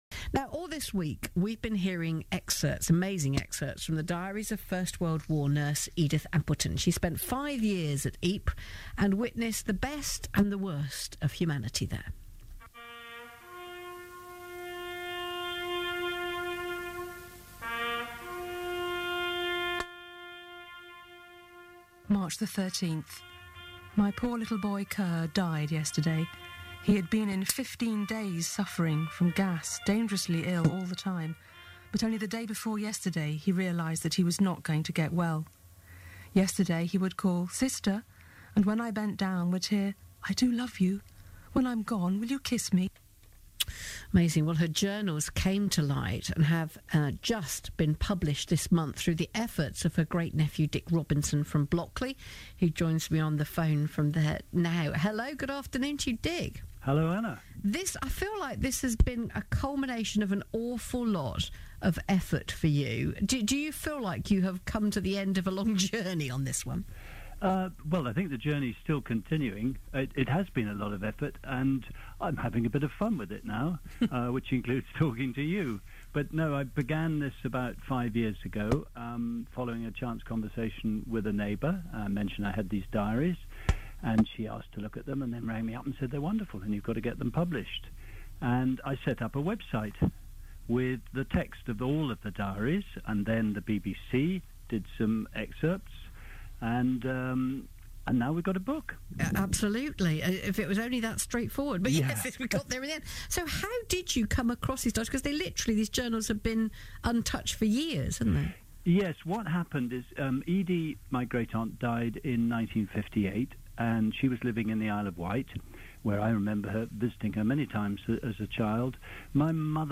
RadioGlosInterview.wma